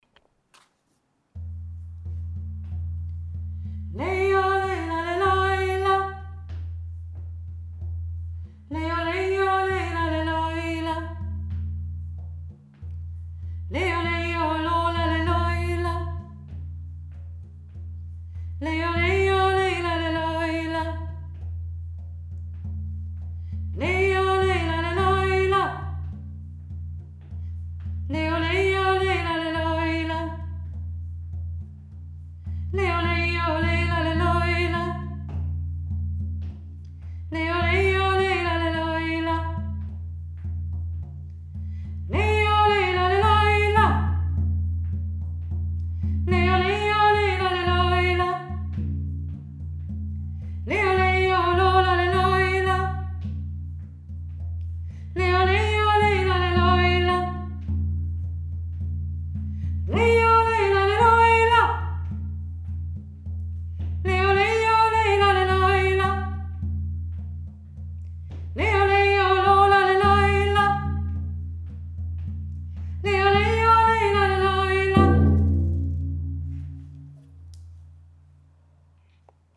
Yoik aus Lappland - einfach nachsingen :-)